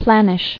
[plan·ish]